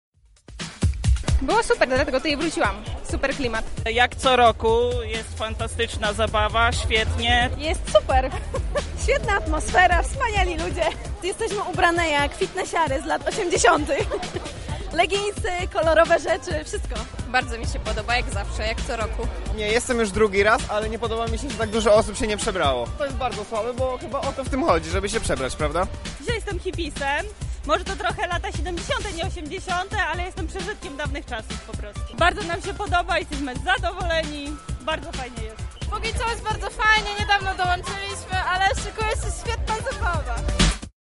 Nasi reporterzy spytali uczestników o ich wrażenia: